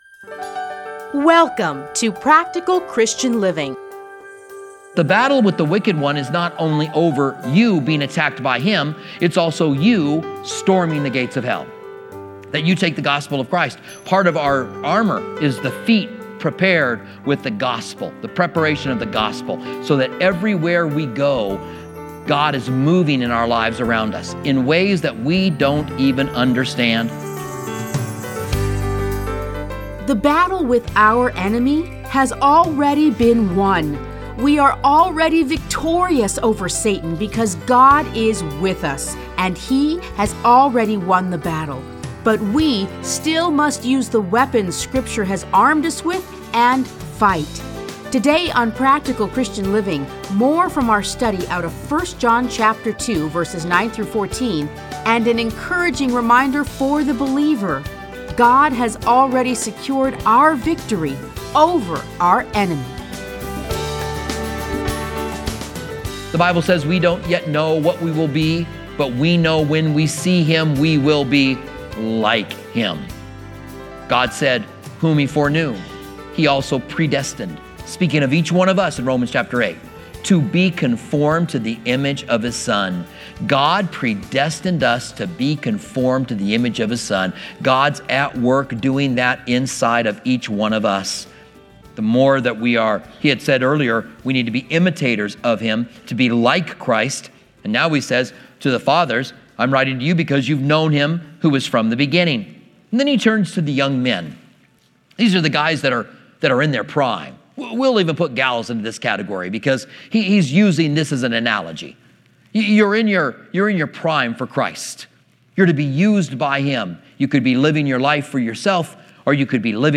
Listen to a teaching from 1 John 2:9-14.